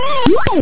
sample03.wheee_.mp3